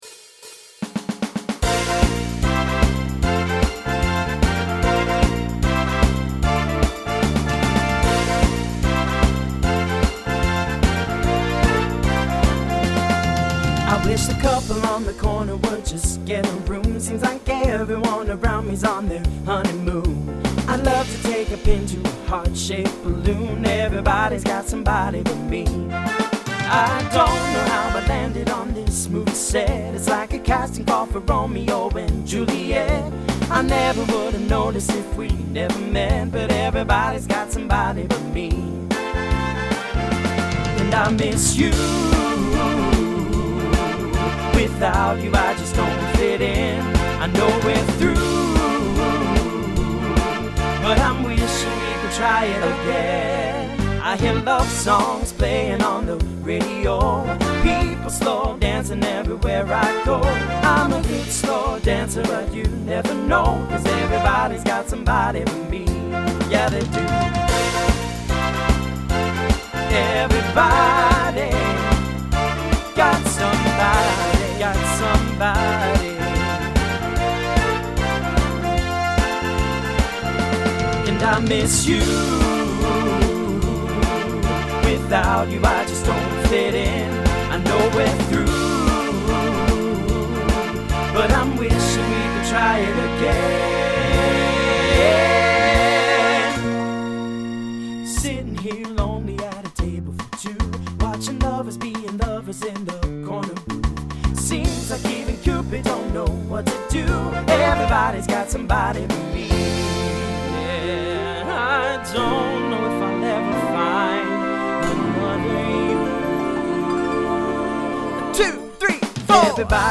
Voicing TTB Instrumental combo Genre Country
Mid-tempo